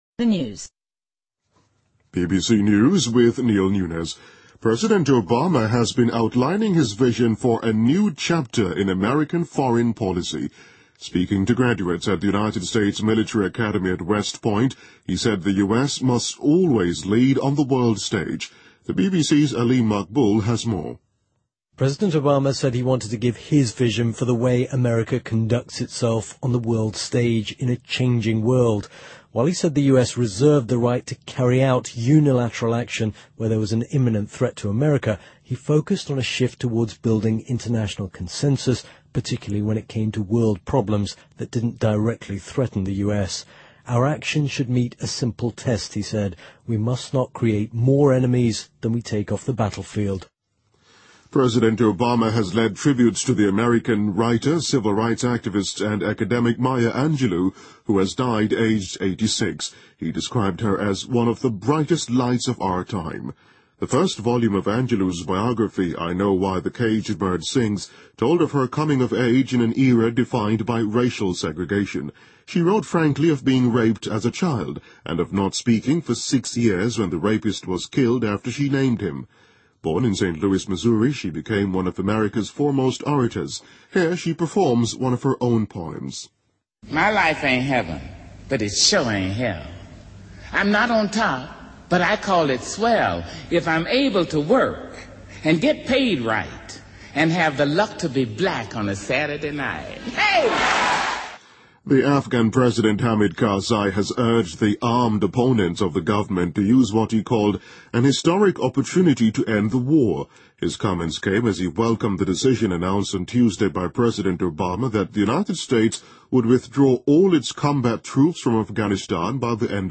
BBC news,美国著名女作家、诗人玛娅·安杰洛(Maya Angelou)去世,享年86岁